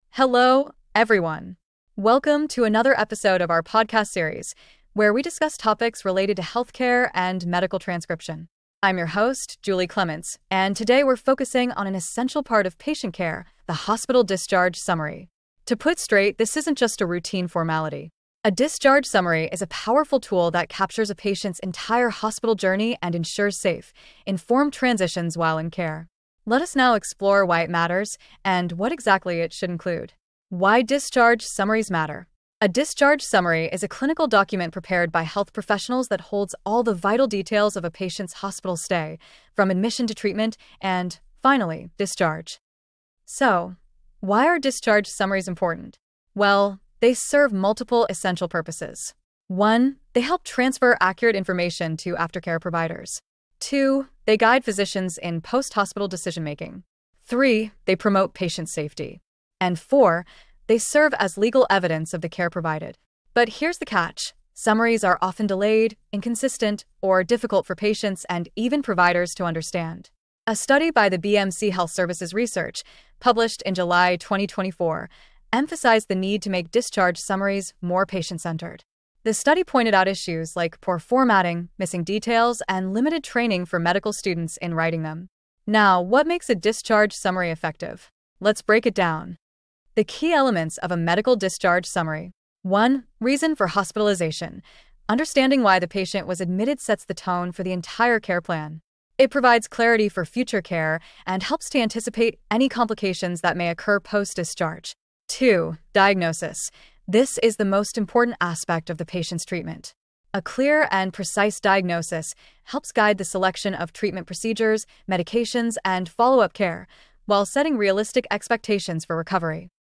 Disclaimer: This podcast blends human-written content with AI voice narration, created for informational use only.